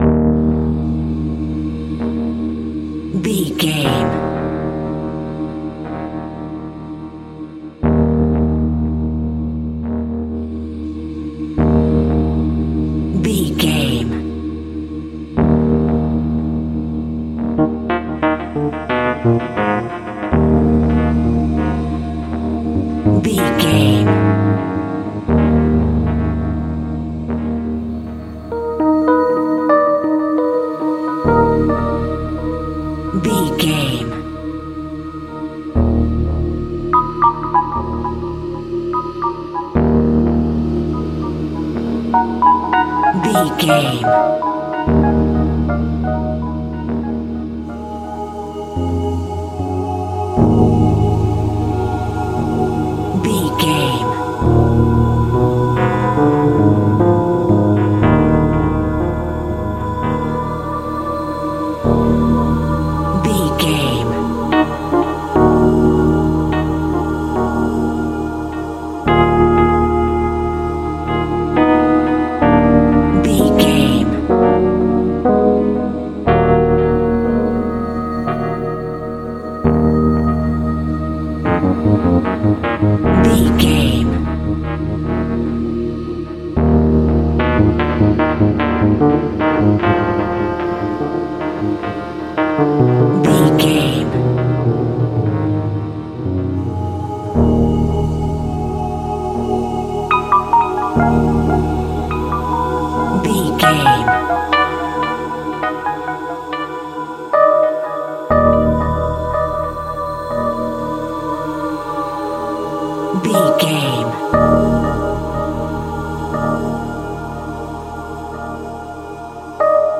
In-crescendo
Thriller
Aeolian/Minor
tension
ominous
suspense
eerie
synths
Synth Pads
atmospheres